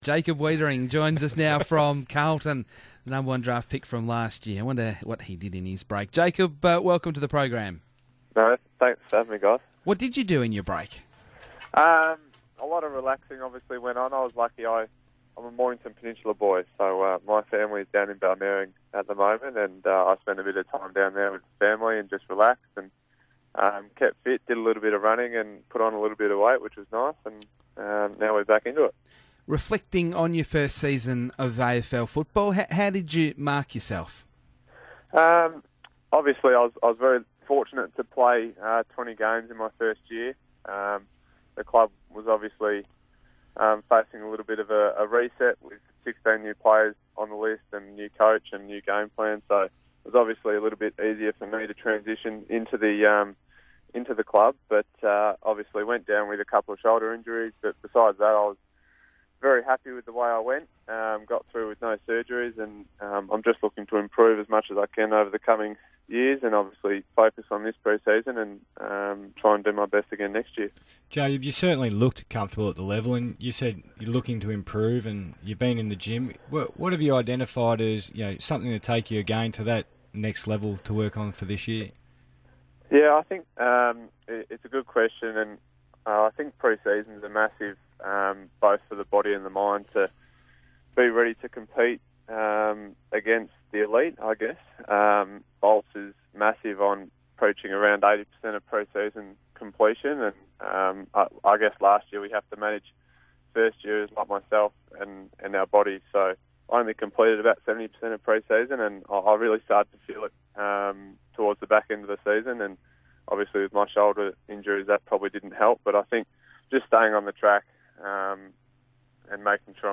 As the full squad returned for their first pre-season training session, second-year player Jacob Weitering spoke to the RSN breakfast program about his break and goals for 2017.